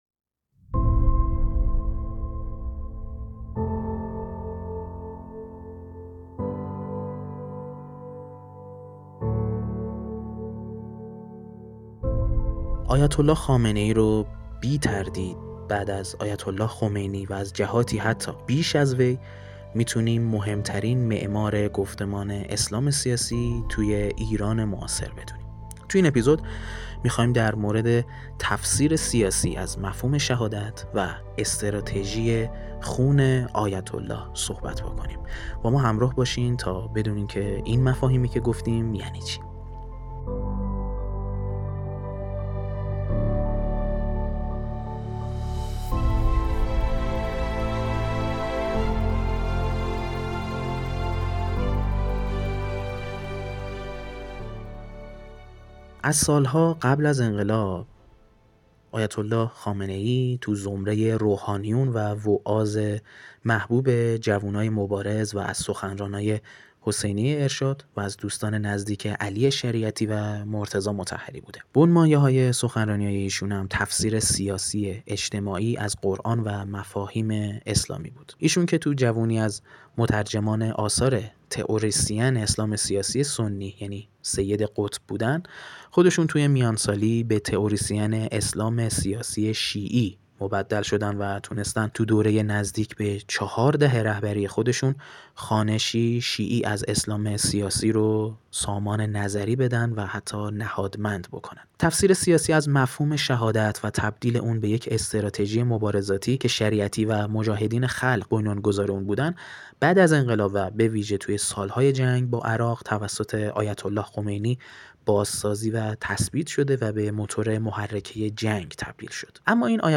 آناکست؛ مستند